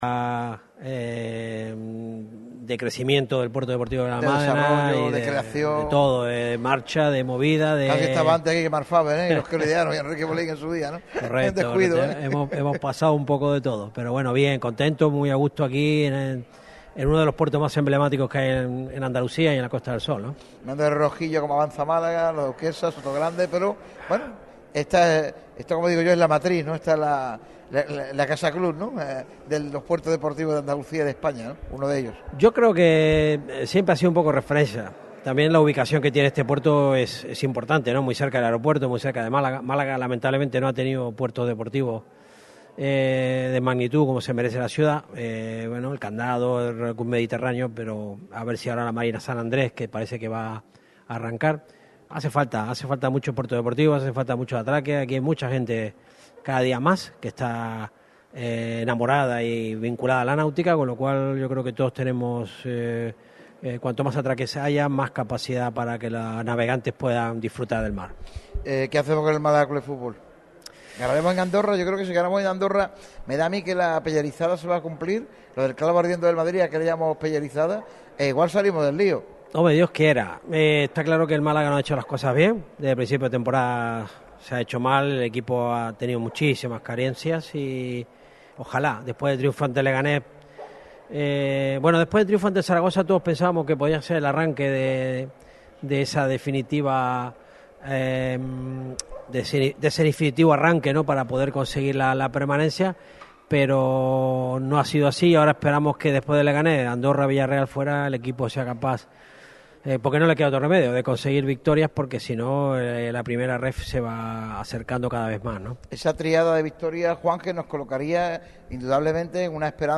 Radio Marca Málaga ha hecho su programa especial desde el Puerto Deportivo de Benalmádena.